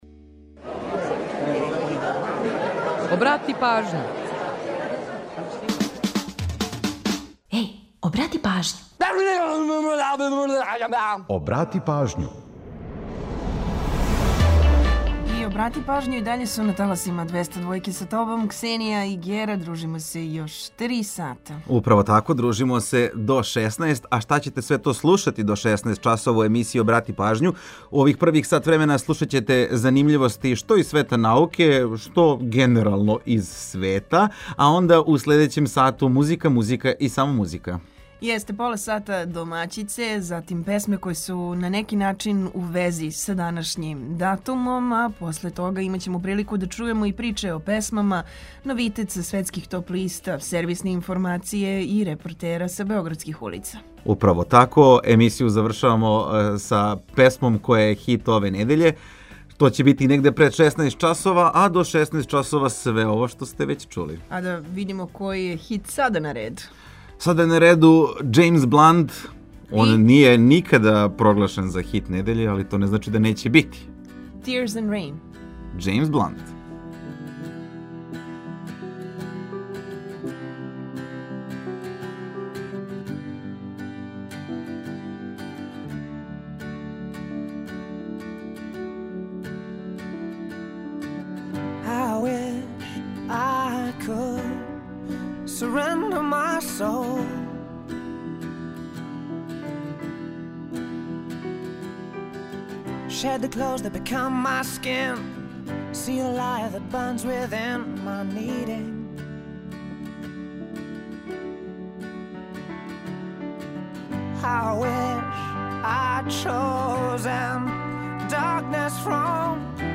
Ту је и пола сата резервисано само за музику из Србије и региона, а упућујемо вас и на нумере које су актуелне. Чућете и каква се то посебна прича крије иза једне песме, а за организовање дана, ту су сервисни подаци и наш репортер.